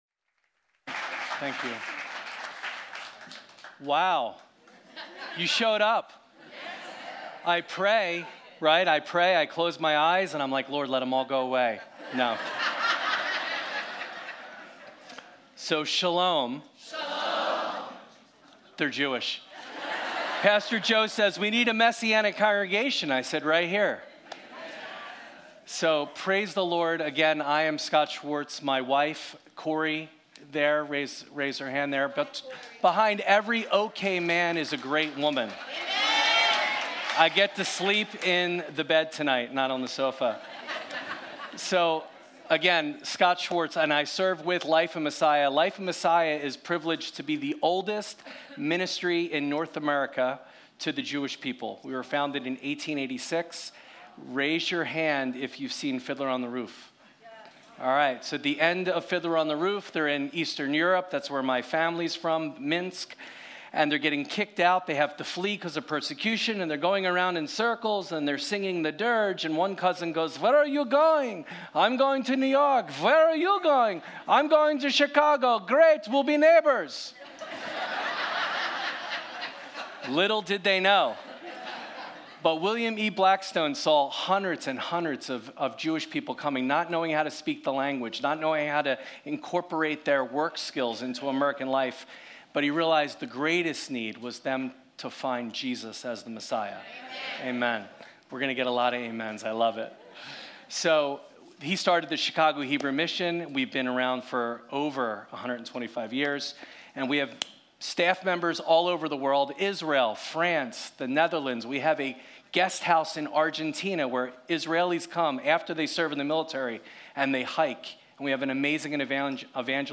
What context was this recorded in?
Midweek Seder || Guest Speaker